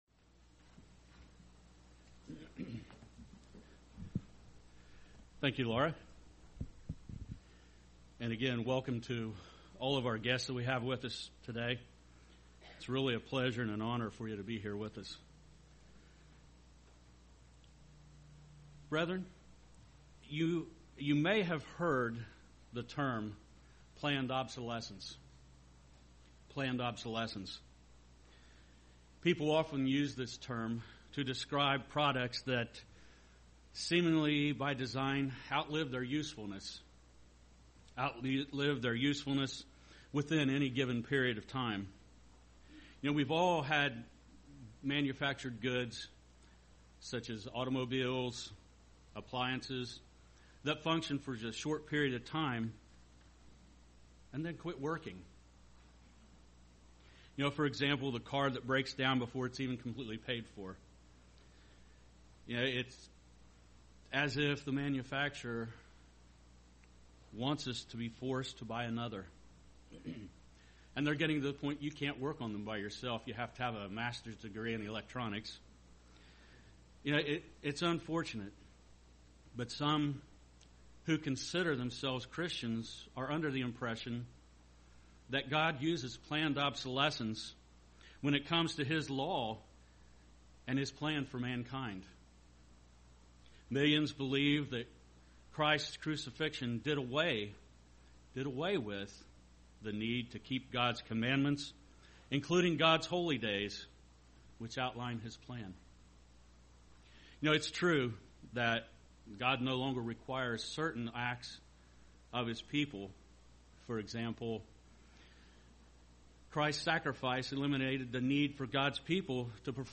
Sermons
Given in Portsmouth, OH